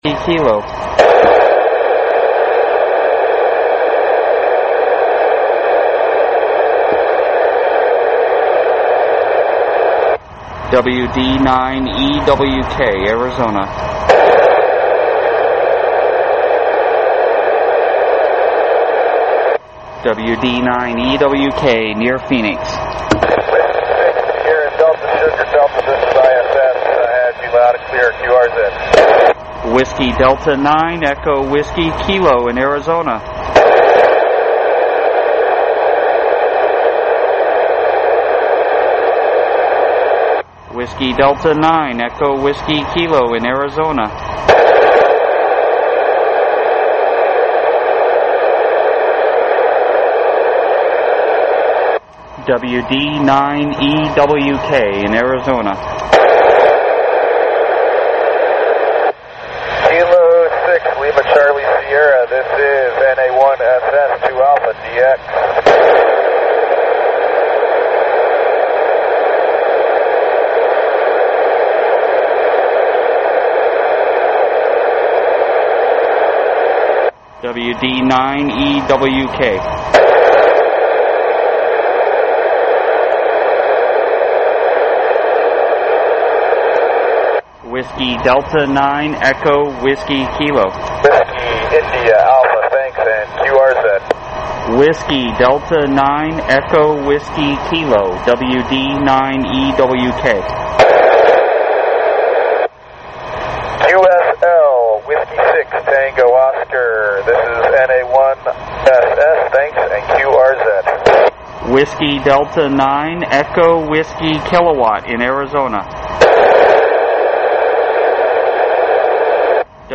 Audio is from 1815-1822 UTC, as the ISS was passing over the western and central parts of the continental USA.